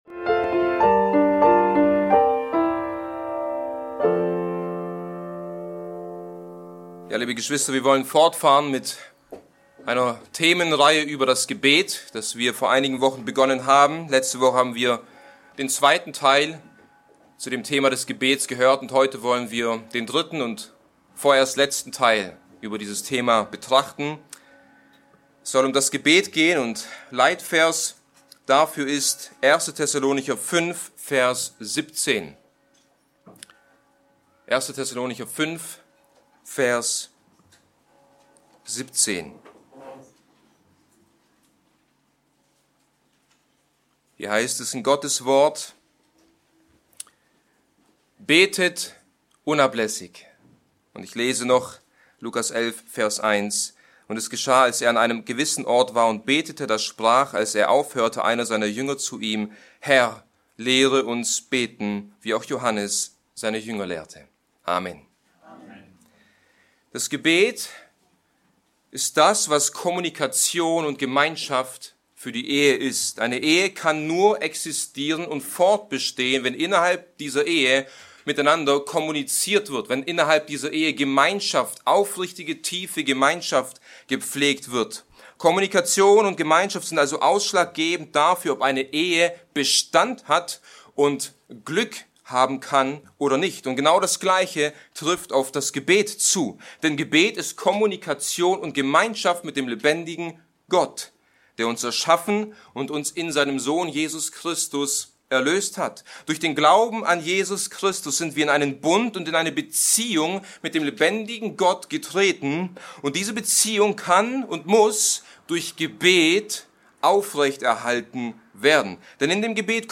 Diese Predigt lehrt praktische Schritte, um ein wirkungsvolles Gebetsleben zu entwickeln, darunter Prioritätensetzung, feste Gebetszeiten und -orte sowie das Studium biblischer Gebete.